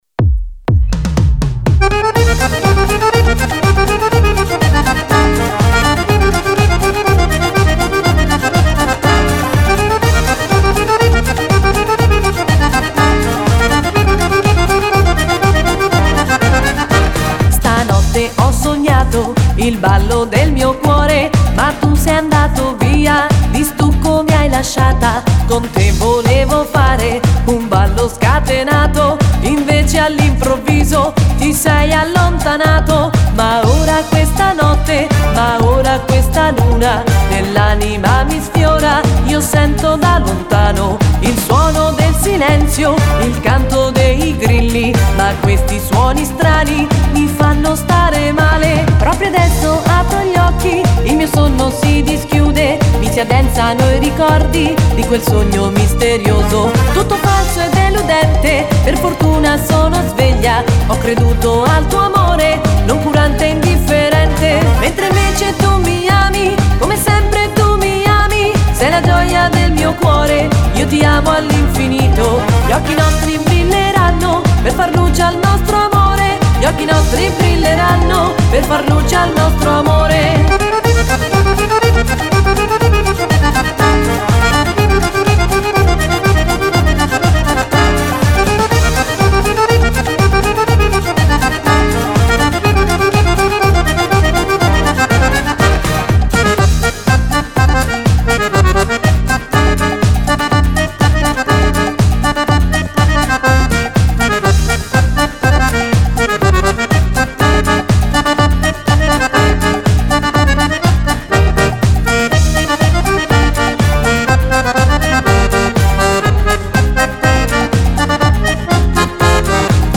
Samba
Dieci canzoni ballabili
Fisarmonica